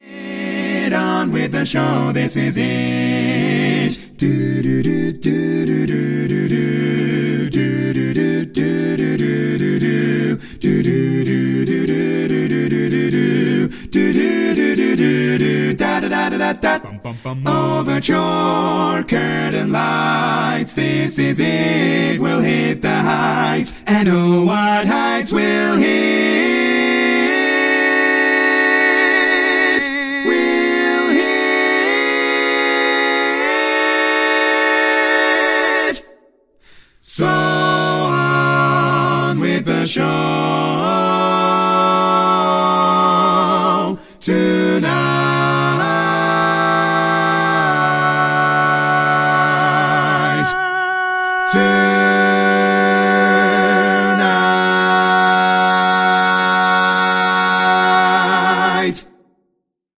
Full mix only